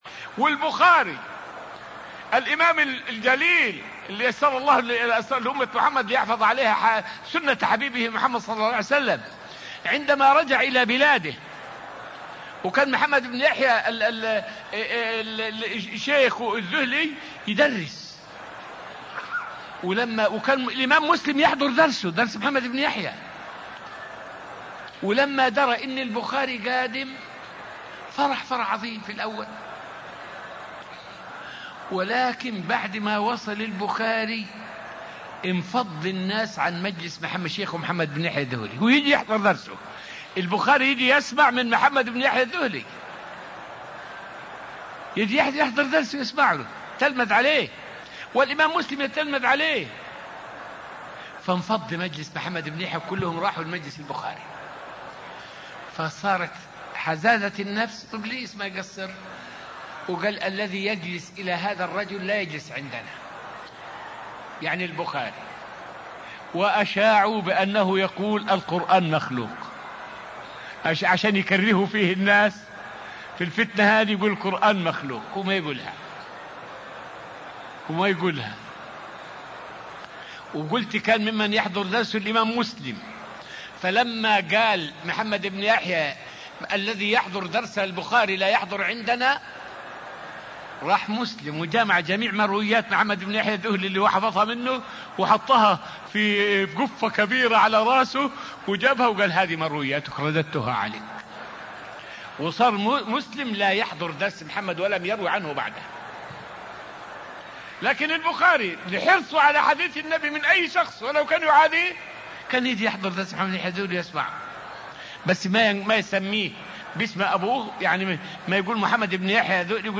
فائدة من الدرس التاسع من دروس تفسير سورة البقرة والتي ألقيت في المسجد النبوي الشريف حول محنة الإمام البخاري.